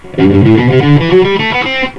Ok, here is the tab, just a 12 note ascending run in E Natural Minor from the F note.
Alternate   D-U-D-U-D-U-D-U-D-U-D-U